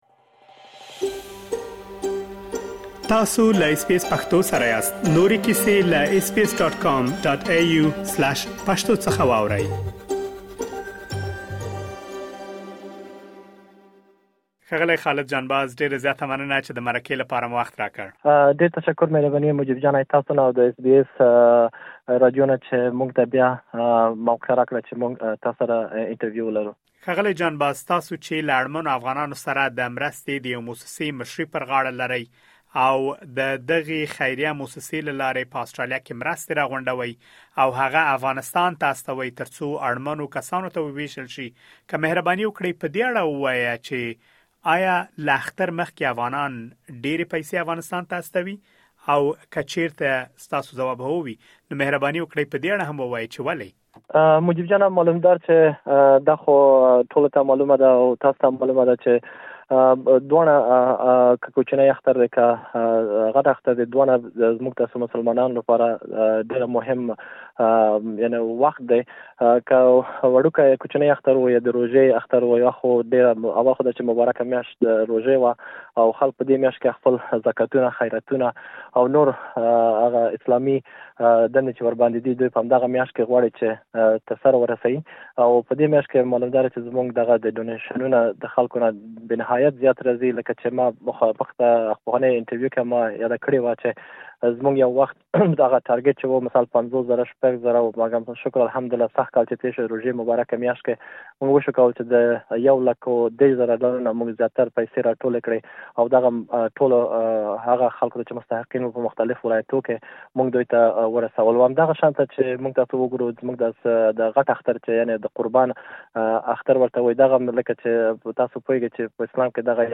تاسو کولای شئ په دې اړه مهم معلومات په مرکې کې واورئ.